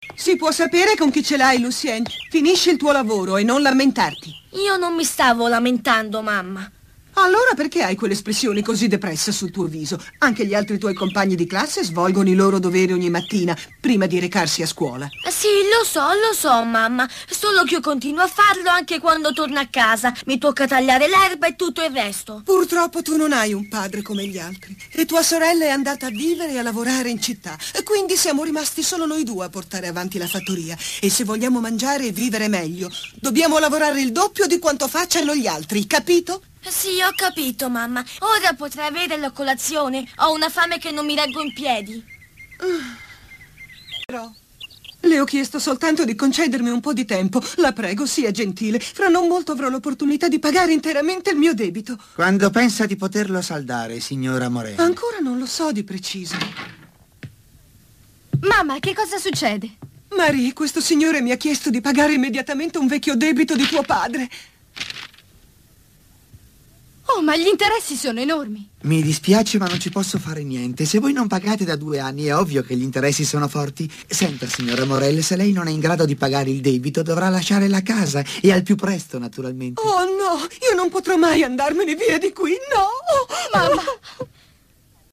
nel cartone animato "Sui monti con Annette", in cui doppia la signora Morel.